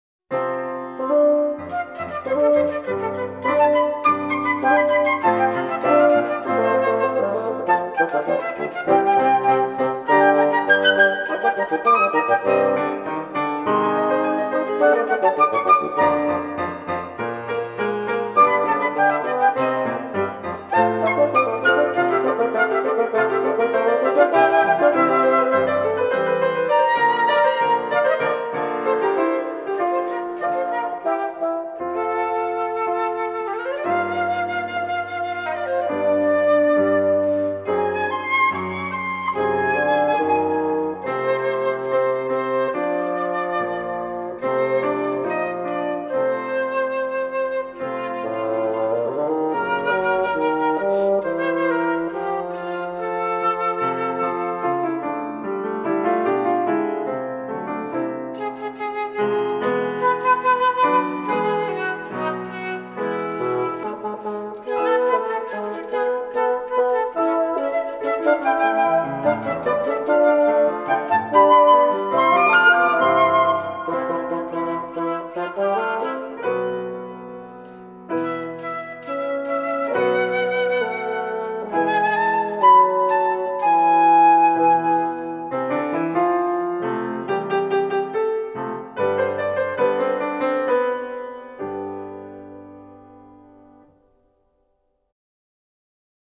Suite for Flute, Bassoon and Piano: 2nd movement